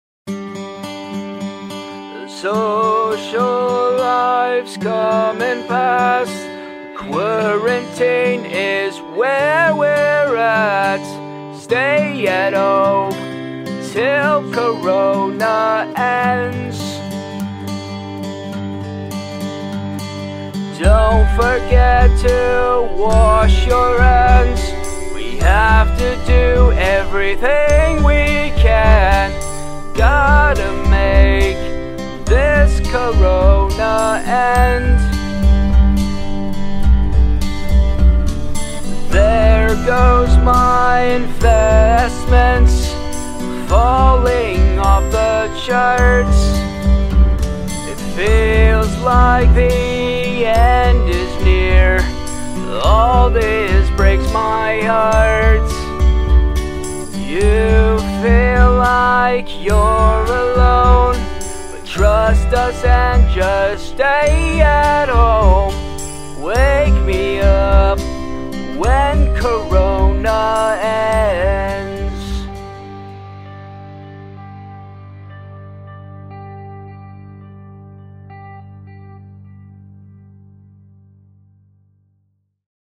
18) Closing Parody Song